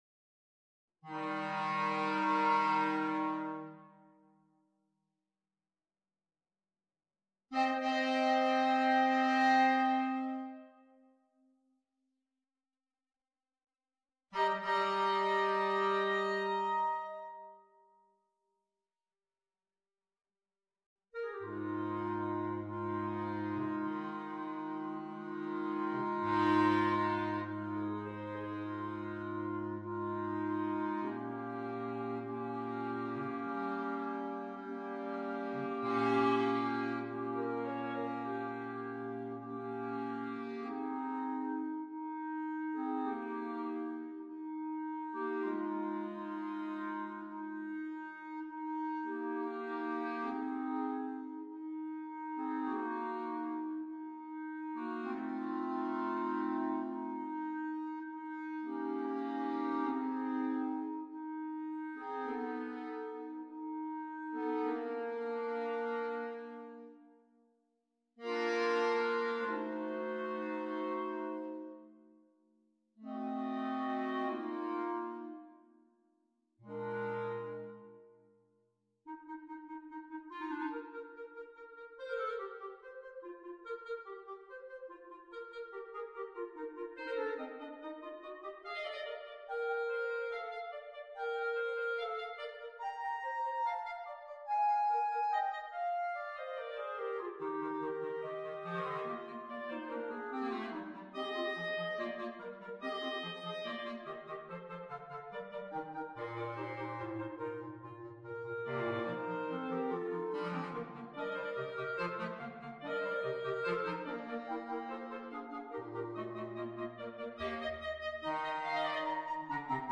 clarinetto piccolo , corno di bassetto